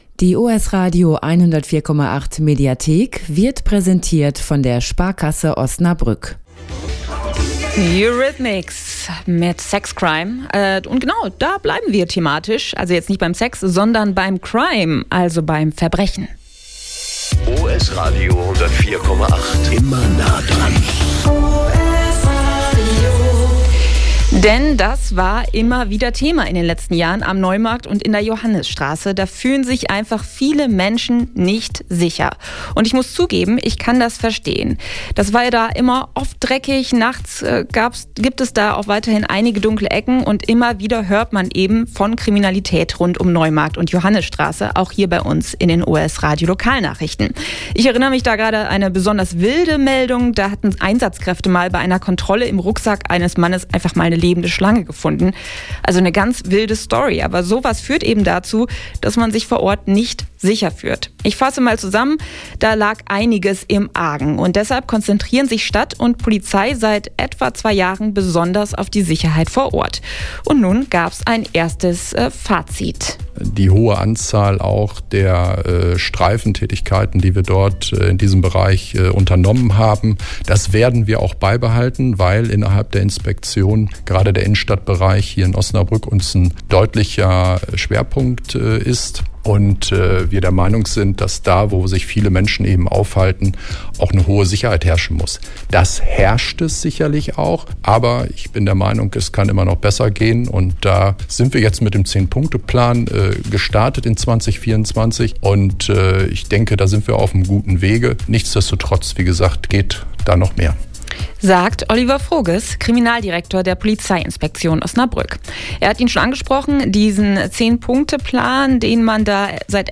Was sich konkret verbessert hat – und wo noch Handlungsbedarf besteht – hört ihr im Mitschnitt.